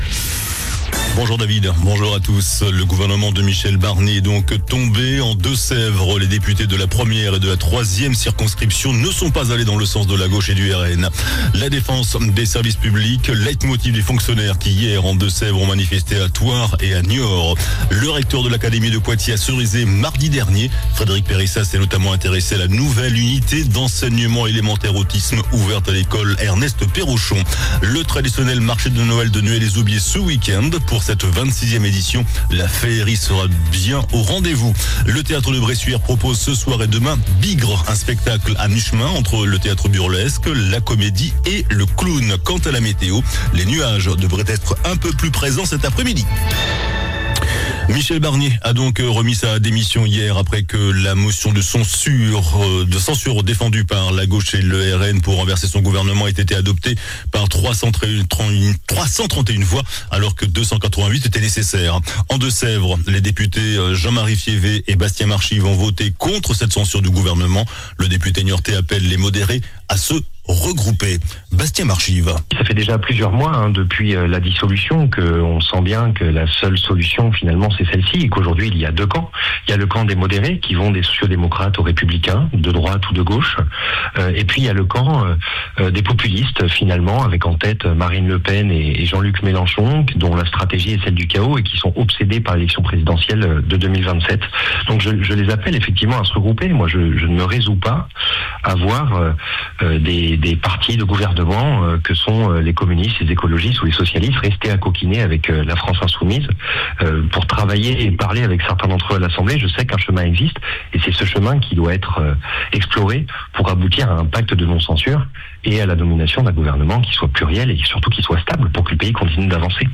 JOURNAL DU VENDREDI 06 DECEMBRE ( MIDI )